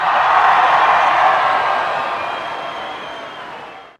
Stadium Cheering
A massive stadium crowd erupting in cheers with whistles, clapping, and stomping feet
stadium-cheering.mp3